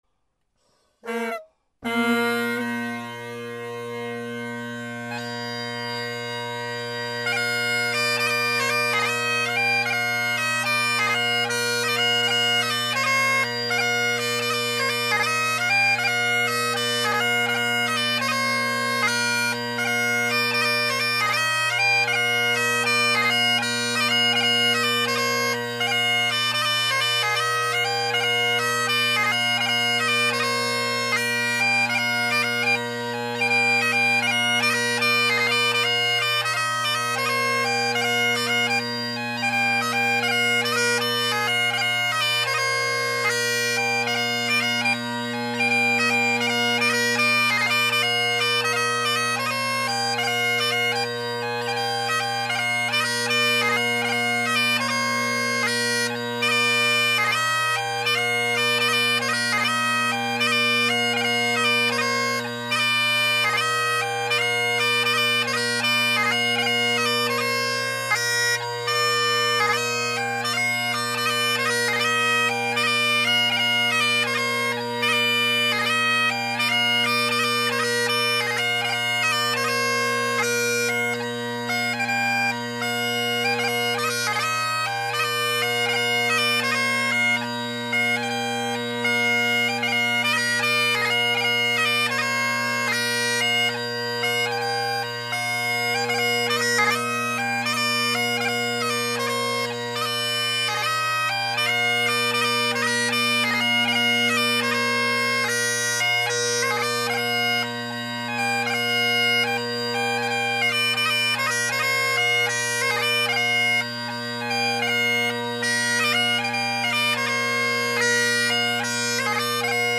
Great Highland Bagpipe Solo
6/8’s: